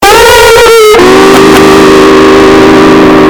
Spongebob Fail Sound Bass Boosted